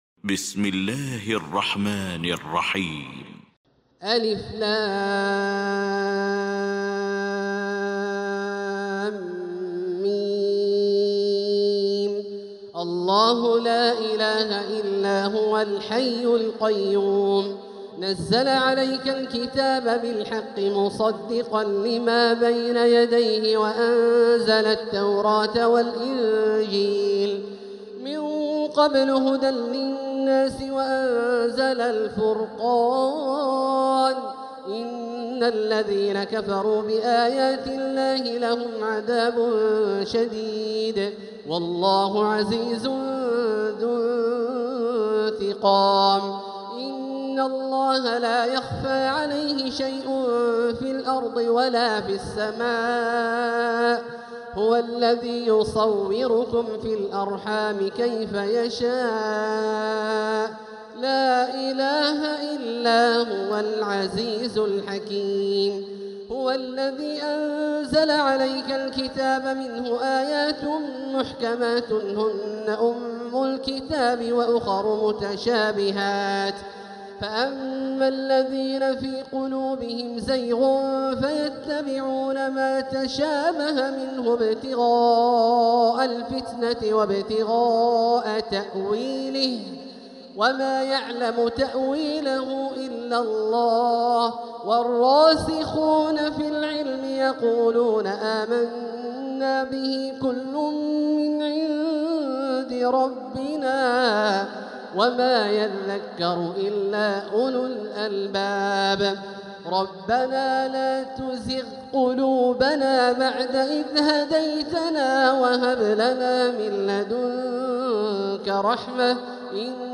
سورة آل عمران Surat Aal-i-Imraan > مصحف تراويح الحرم المكي عام 1446هـ > المصحف - تلاوات الحرمين